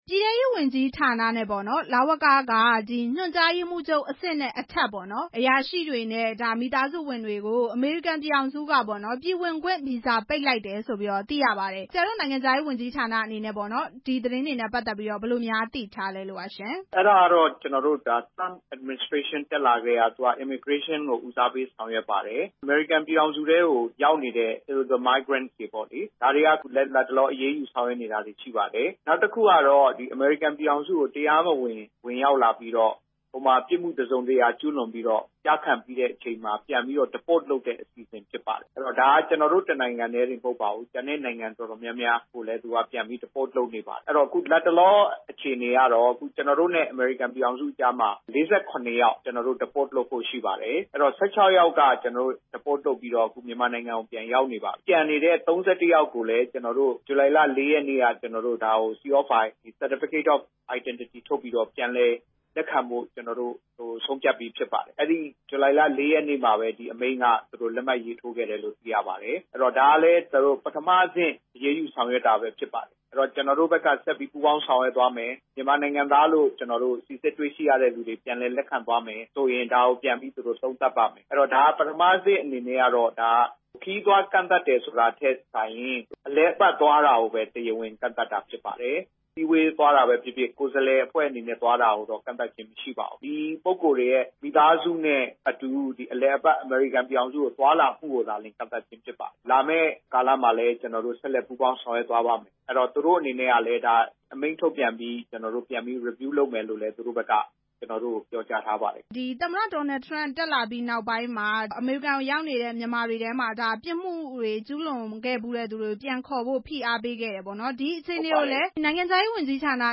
အဲဒီအတွက်ကြောင့် ဝန်ကြီးဌာနနှစ်ခုက အရာရှိတွေနဲ့ မိသားစုဝင်တွေကို အမေရိကန်ပြည်ထောင်စုက ပြည်ဝင်ခွင့် ပိတ်ပင်လိုက်တဲ့ကိစ္စအပေါ် အမေရိကန်အစိုးရဘက်က ပြန်လည်သုံးသပ်ဖို့ရှိတယ်လို့ မြန်မာနိုင်ငံရေးဝန်ကြီးဌာန အမြဲတမ်းအတွင်းဝန် ဦးမြင့်သူက ပြောပါတယ်။